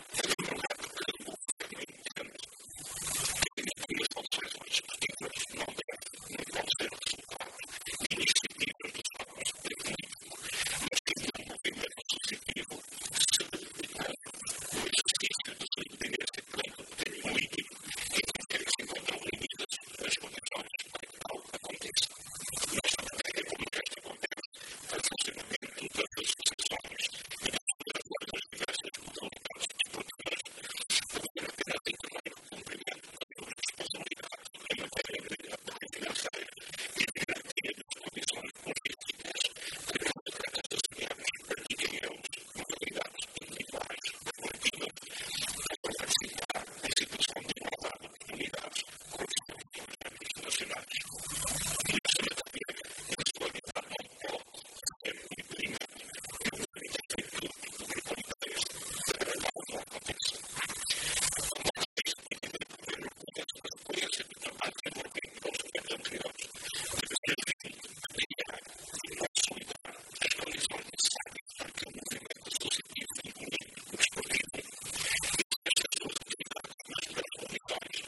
Luiz Fagundes Duarte, que falava na Assembleia Legislativa durante a discussão de um projeto de resolução que recomendava ao Governo dos Açores a criação de seleções desportivas regionais”, reconheceu a bondade desta iniciativa, mas frisou a discordância do Executivo sobre o assunto.